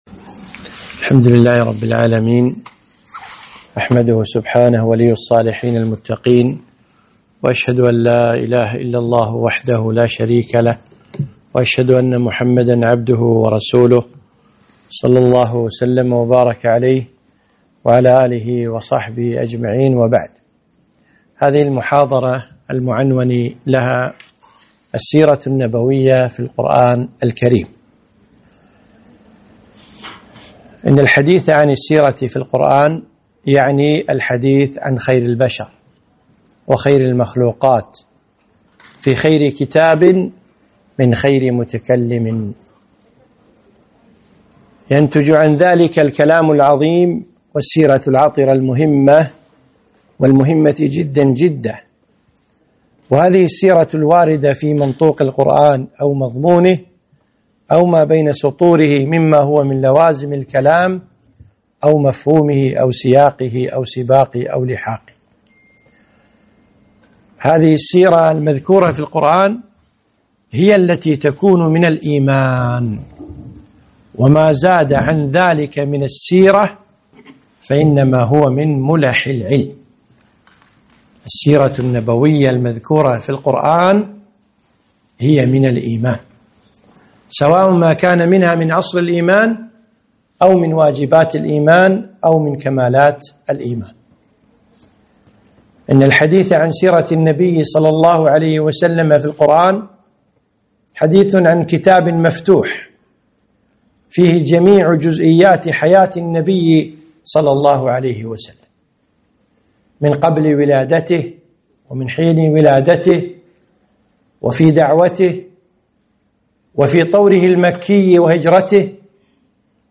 محاضرة - السيرة في القرآن